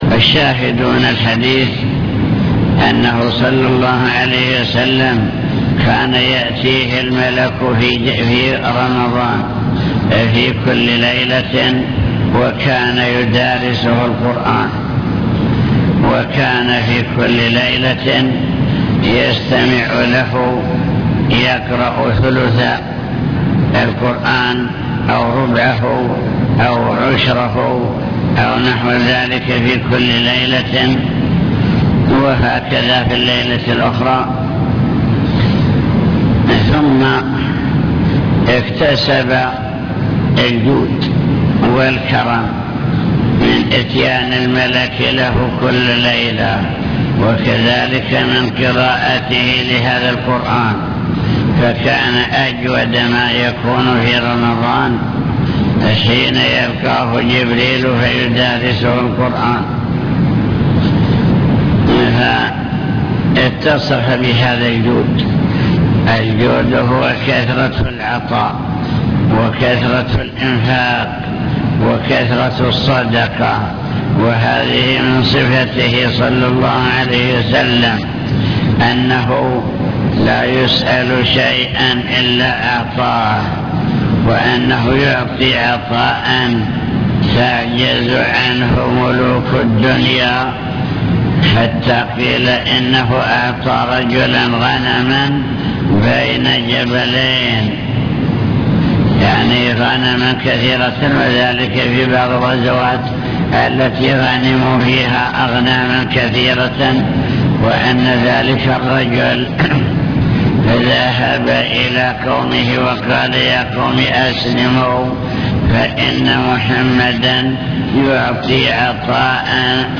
المكتبة الصوتية  تسجيلات - كتب  باب بدء الوحي من صحيح البخاري شرح حديث كان رسول الله صلى الله عليه وسلم أجود الناس